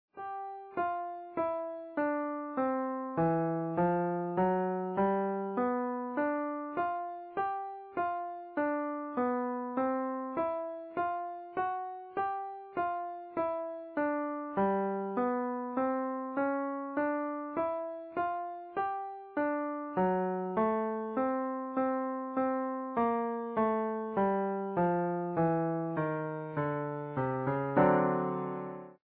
The 'muddy' lick through 12 bars.
Here we take the 'muddy' licks from above and create a 12 bar blues form / song.